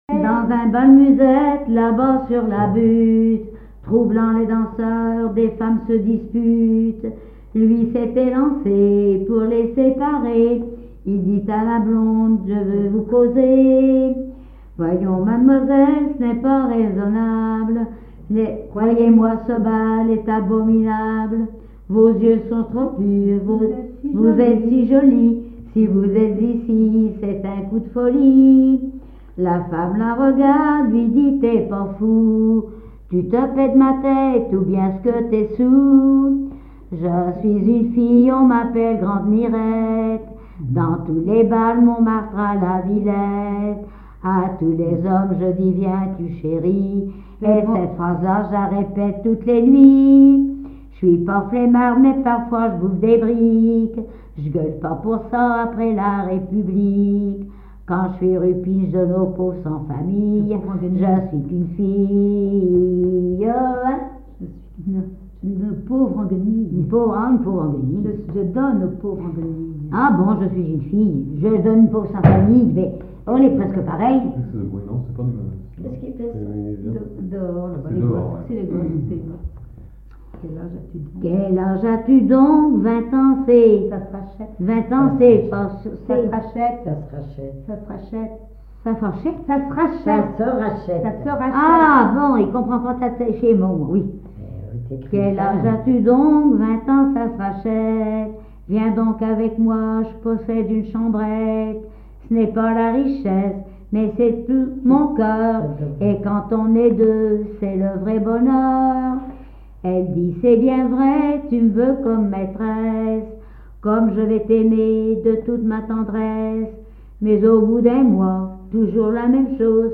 Localisation Saint-Gilles-sur-Vie
Genre strophique
Catégorie Pièce musicale inédite